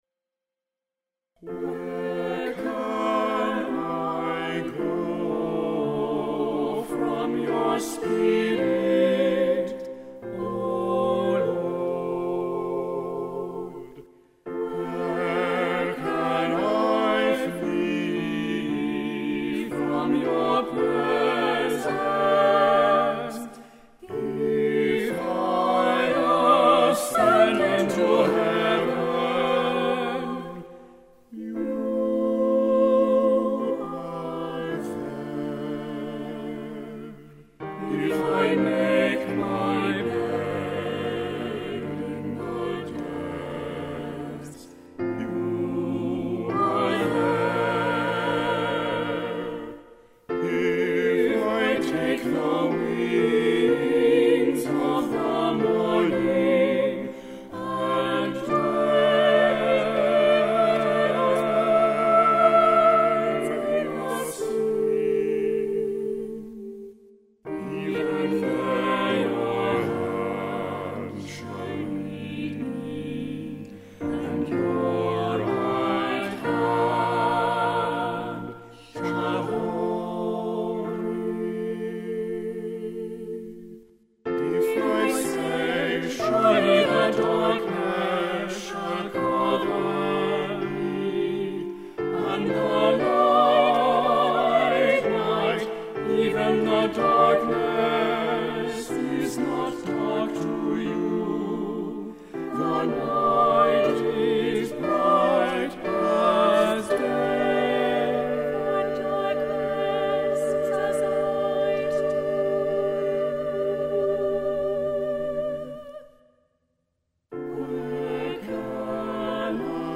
Flute
Trumpets (C) 1 & 2
Percussion (1 player)
SATB Choir
Cellos
Piano